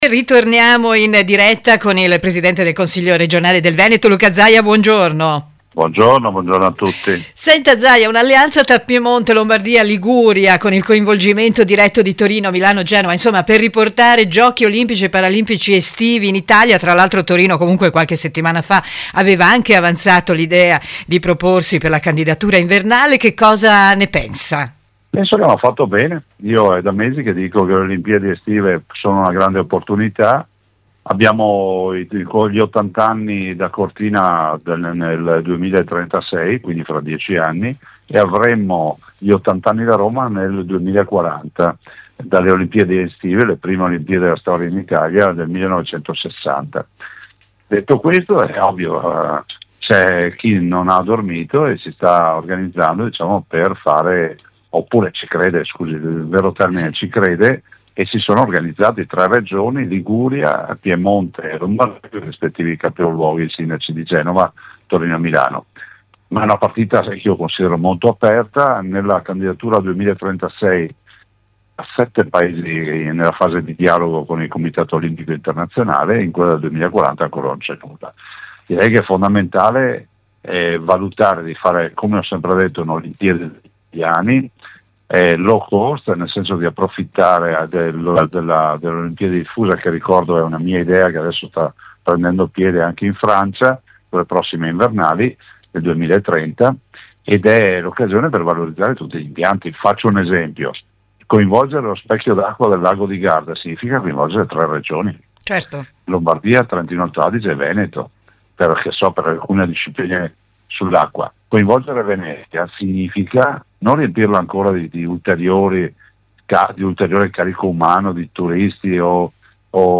OSPITE: Luca Zaia